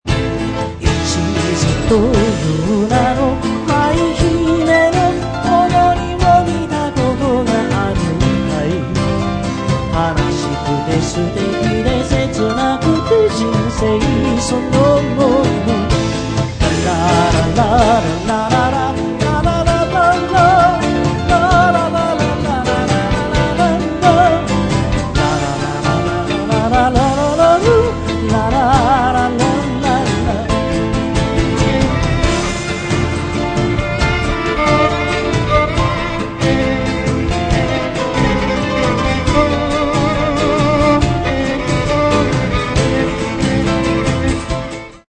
発売当時、通算回数3,500回余りを数えるコンサートの中から、厳選に厳選を重ねた51曲を収録。